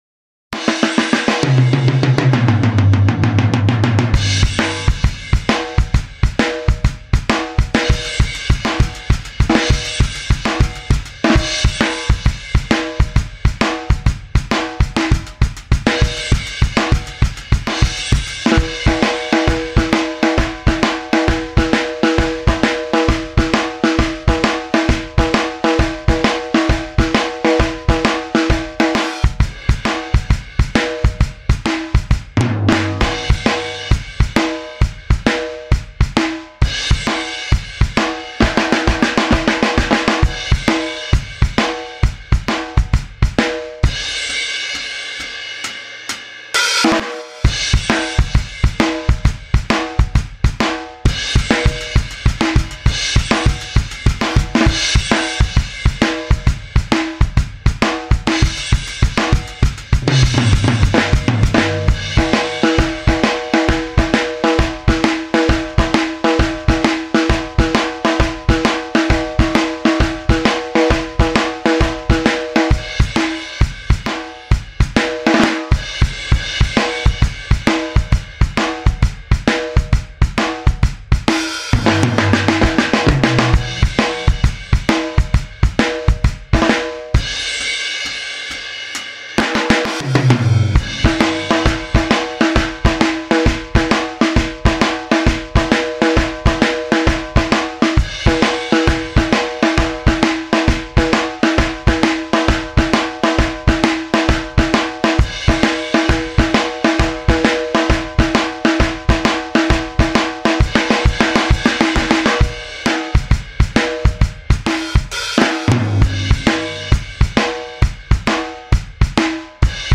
Studio Kit